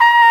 BRS TRMPPB0M.wav